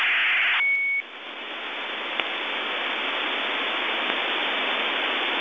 Начало » Записи » Радиоcигналы на опознание и анализ
BPSK 1333.51 bps